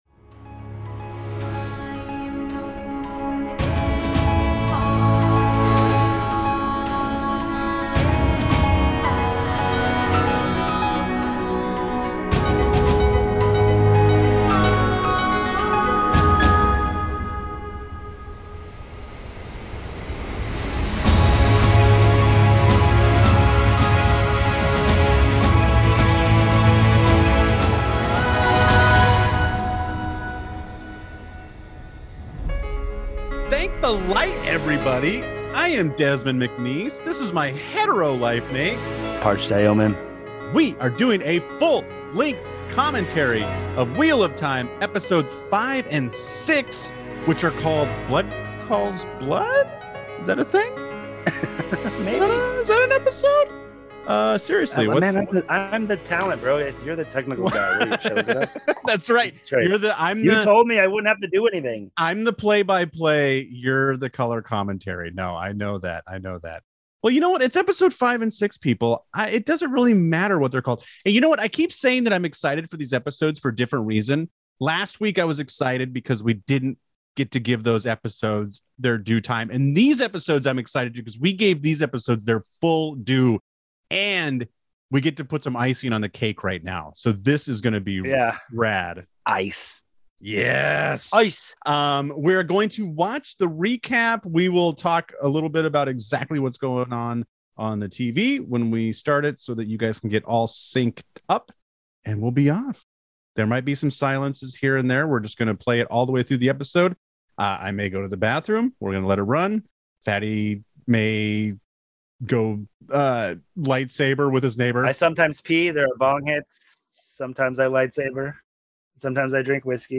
With every episode comes a little more silence as we experience these episodes in real time and get into what is happening on screen. Press play on the podcast then press play on Amazon and take us with you as you relive exciting moments from season one.